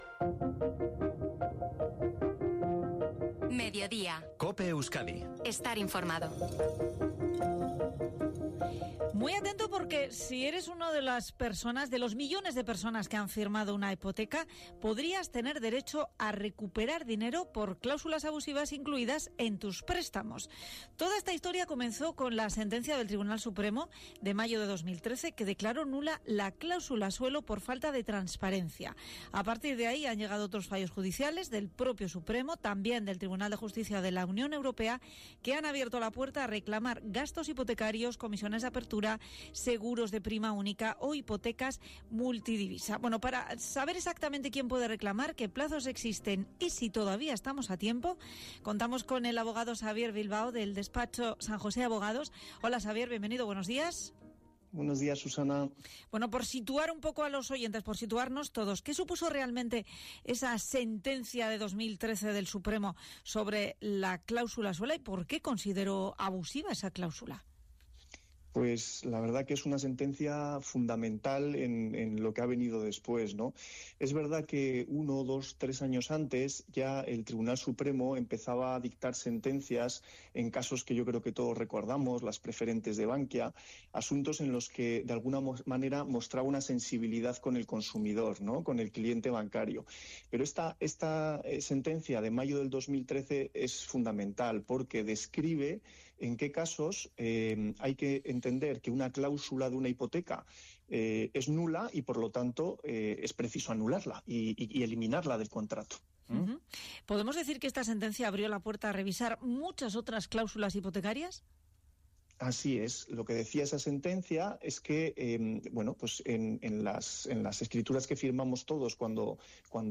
ENTREVISTA EN COPE SOBRE RECLAMACIONES HIPOTECARIAS - Despacho Abogados San Jose